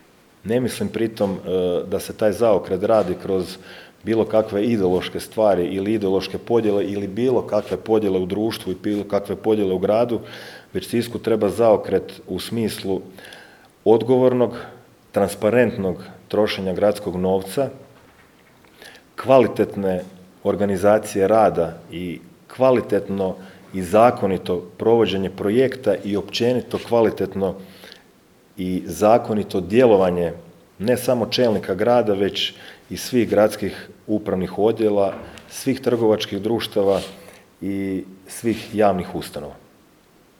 „Više od 22,3 milijuna eura duga, milijunska kreditna zaduženja, nezakonita gradnja, nestali milijuni… Zatečeno stanje u Gradu Sisku nakon odlaska bivše uprave je alarmantno. Nije riječ samo o financijama, riječ je o duboko narušenom sustavu u kojem su ugrožene osnovne funkcije grada”, istaknuo je danas na tiskovnoj konferenciji novi gradonačelnik Siska Domagoj Orlić